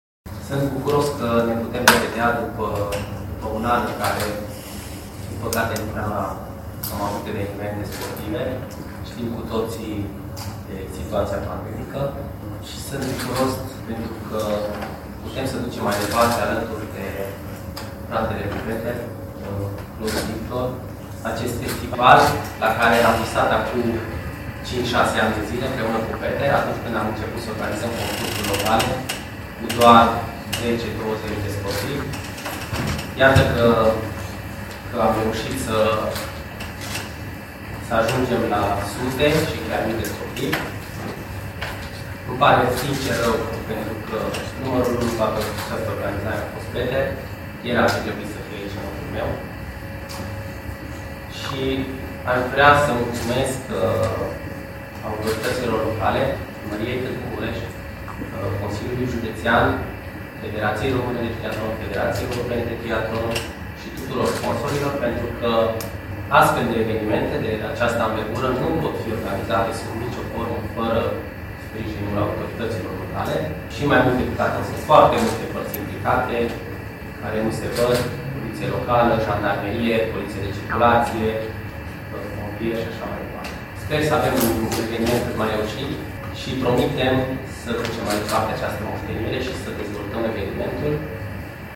La conferința de presă organizată joi, 1 iulie, la Hotel Grand din Tg.-Mureș, cu prilejul Campionatelor Europene de duathlon